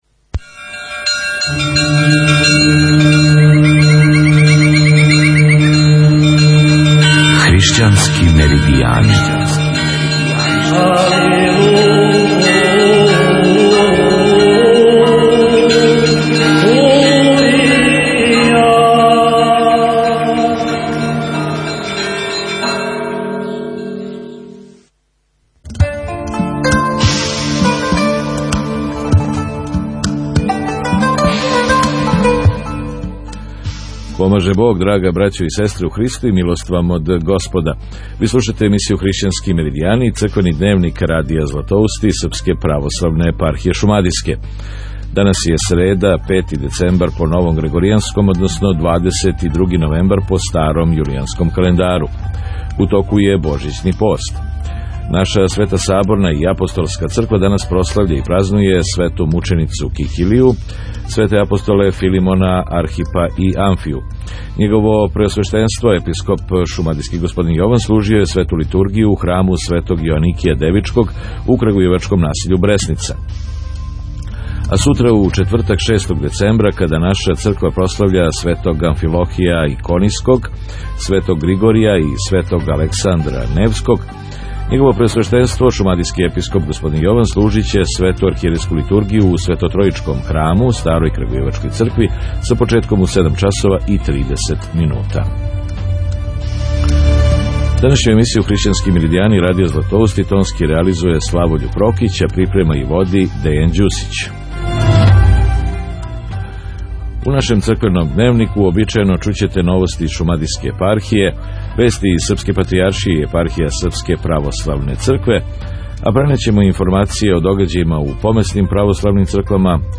Поштовани слушаоци, слушате ХРИШЋАНСКЕ МЕРИДИЈАНЕ, издање вести из наше православне Епархије шумадијске, Српске православне цркве, помесних цркава и других верујућих заједница Радија „Златоусти” за четвртак, 6. децембар по грегоријанском а 23. новембар по јулиjанском календару. У нашој светој, саборној и апостолској цркви данас се славе и празнују: СВЕТИ АМФИЛОХИЈЕ ИКОНИЈСКИ, СВЕТИ ГРИГОРИЈЕ и СВЕТИ АЛЕКСАНДАР НЕВСКИ Девети је дан и друга недеља Божићног поста.